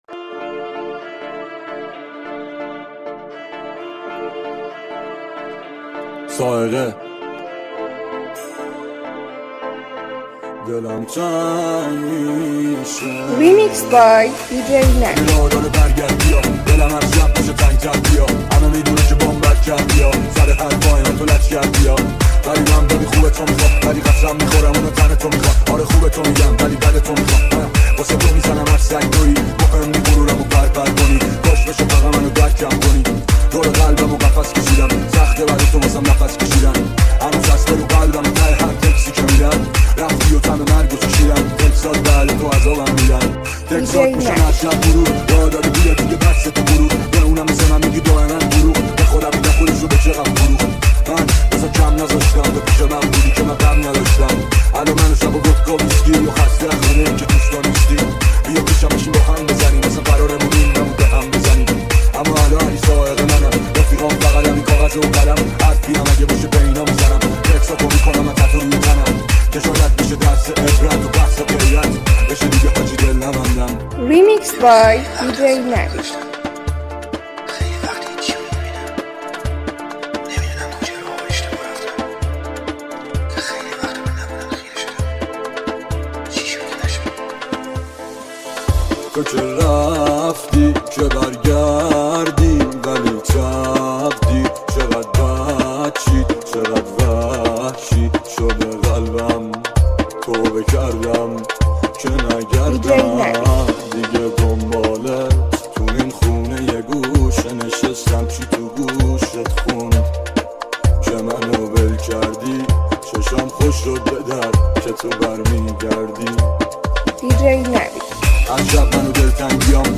ریمیکس تند بیس دار